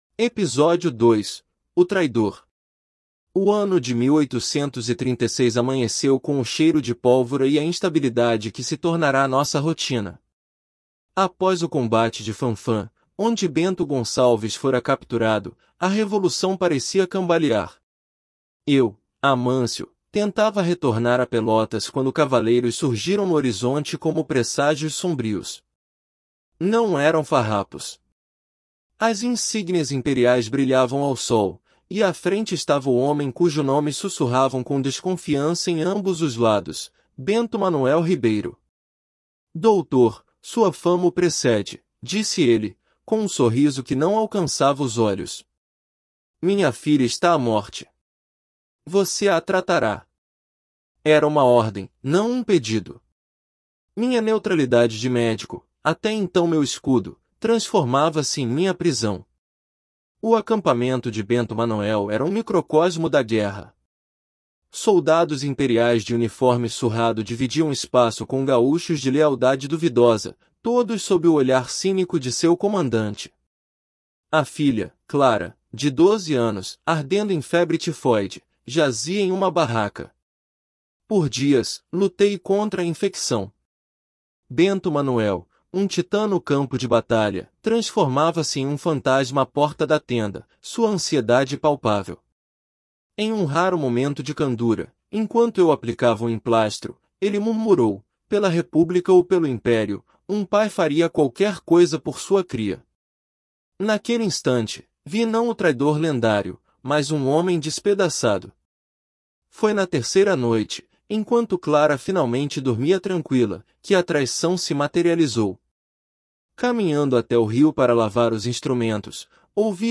• Before reading: Listen to understand rhythm, intonation, and natural speech.